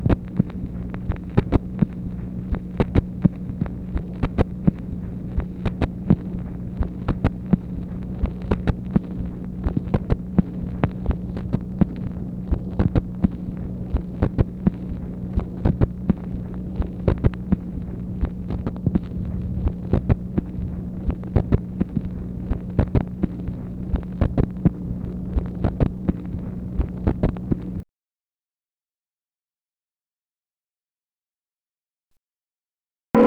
MACHINE NOISE, June 12, 1964
Secret White House Tapes | Lyndon B. Johnson Presidency